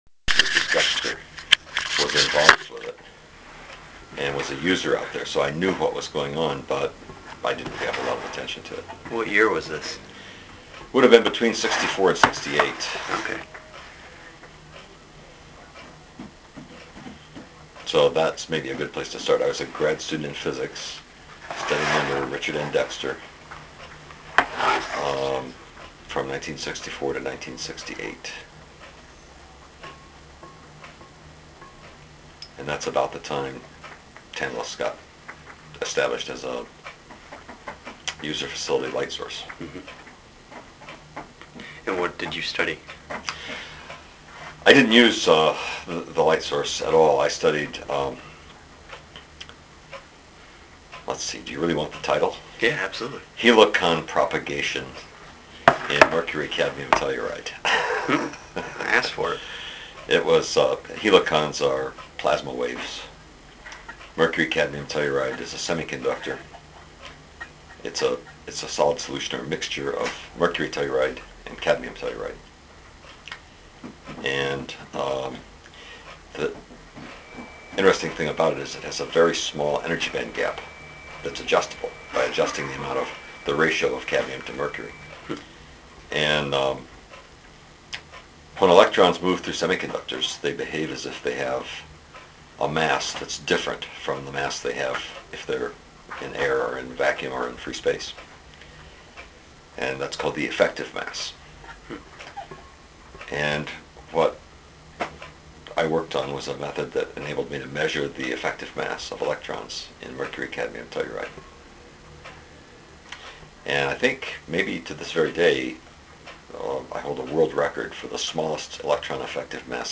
Wiley Oral History
Oral history with John Wiley, user of the Synchrotron Radiation Center, and former Chancellor of the University of Wisconsin-Madison.